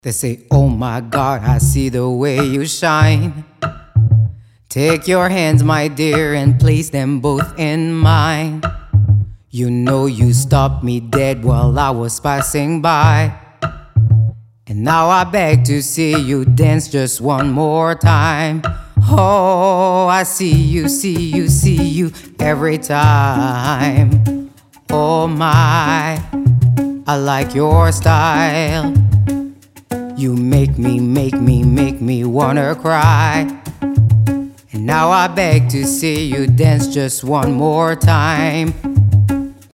• Качество: 320, Stereo
Cover
инструментальные